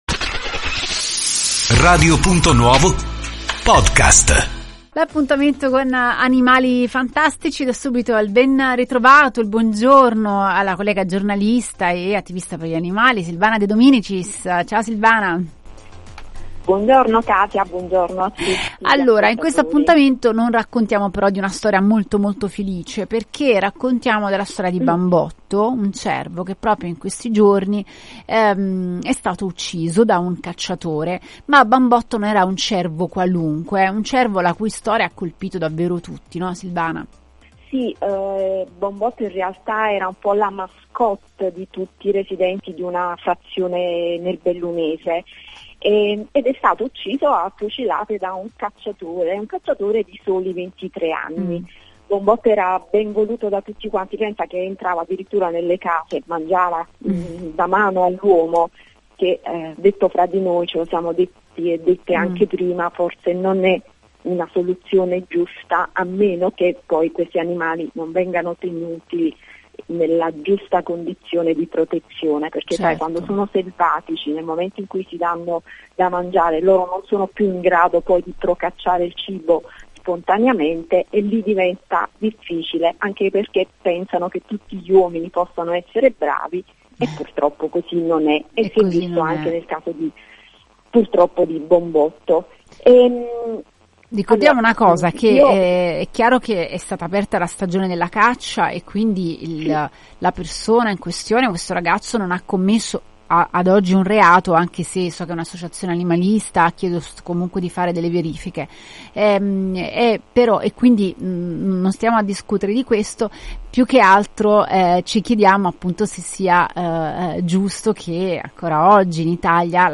Ne abbiamo parlato ad Animali Fantastici con la giornalista e attività per gli animali